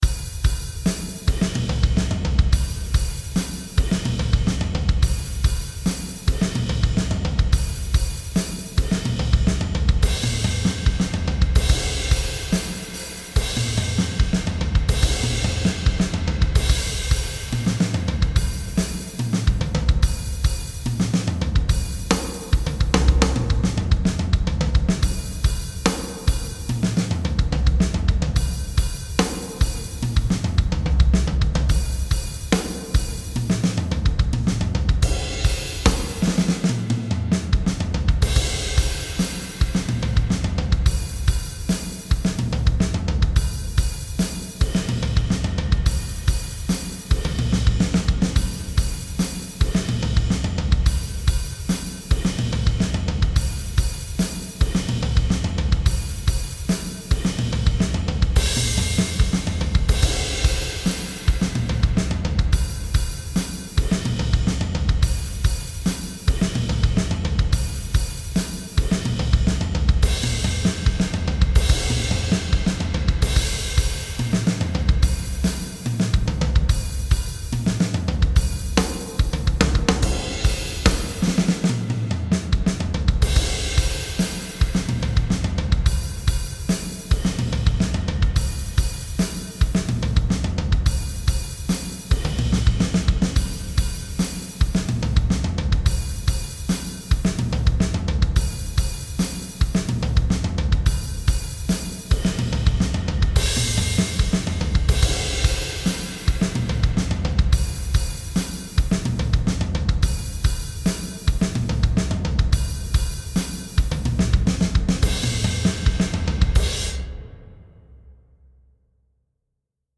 This mp3 of the midi version is pretty much its final form and an accurate transcript of what I’ll be recording in the coming days. I have some ideas for bass, chords and whatnot, but unlike Molten Dry Bips which sounded perhaps a bit too dry without the extras, drums only seems fine this time so not going to risk another disaster and shelve that thought.
The cymbals are close but not quite like the same as what I have setup on my drum kit. The hi-hat is mainly played half-closed or so most of the time, but here I don’t delve into the editor enough to give it more variety in sounds.
august-tom-groove-software-musician.mp3